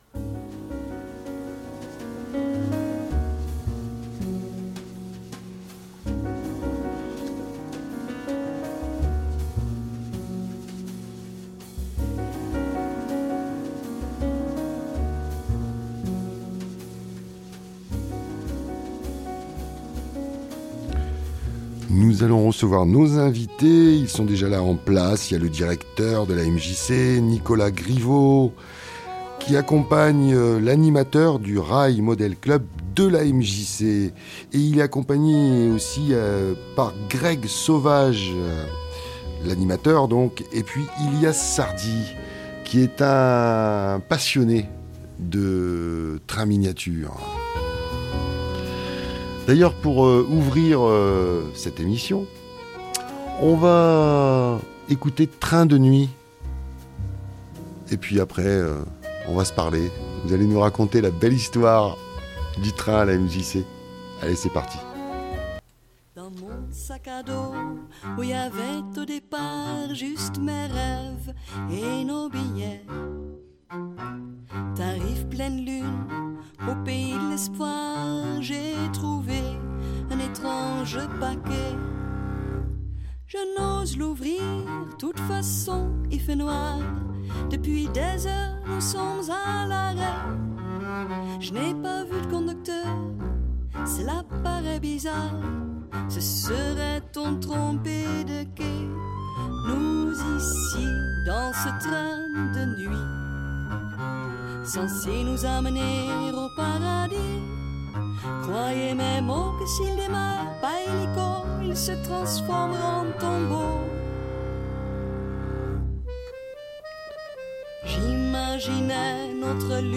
Dans notre entrevue du jour